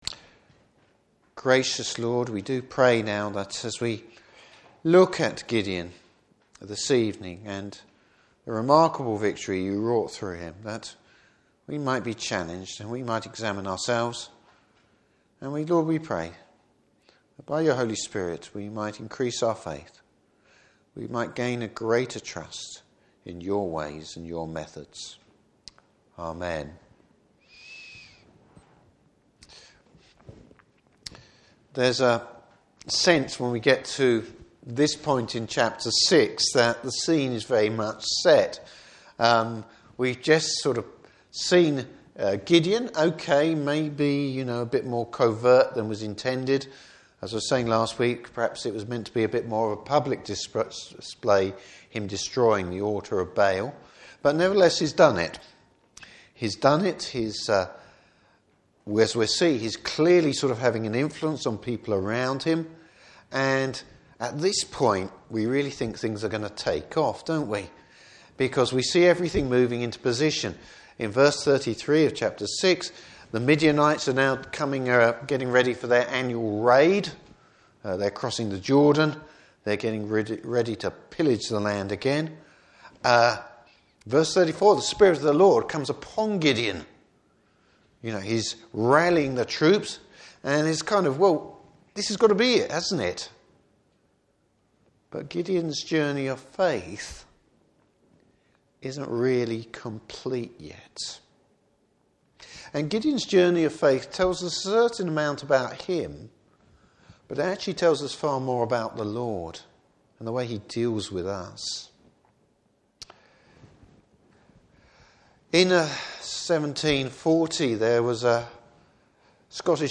Service Type: Evening Service Gideon, growing in faith and the kindness of the Lord.